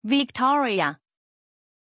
Pronunciation of Victoriah
pronunciation-victoriah-zh.mp3